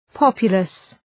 Shkrimi fonetik {‘pɒpjʋləs}